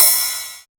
• Subtle Reverb Crash Sound D# Key 03.wav
Royality free crash cymbal one shot tuned to the D# note. Loudest frequency: 9845Hz
subtle-reverb-crash-sound-d-sharp-key-03-tES.wav